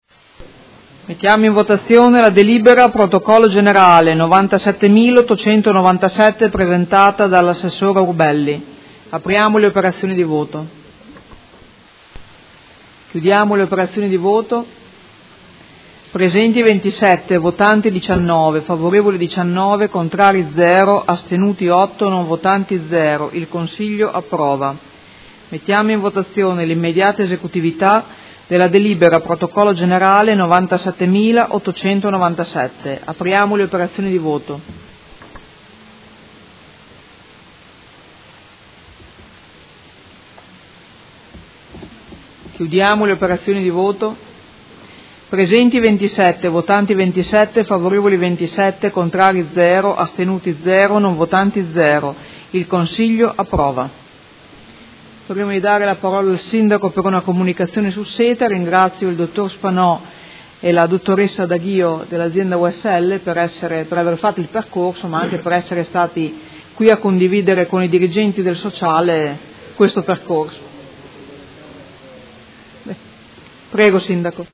Presidente — Sito Audio Consiglio Comunale
Seduta del 12/07/2018. Mette ai voti proposta di deliberazione: Approvazione dell’Accordo di Programma fra Comune di Modena ed Azienda USL di Modena avente ad oggetto il Piano di Zona Triennale 2018-2010 per la Salute e il Benessere sociale e immediata esecutività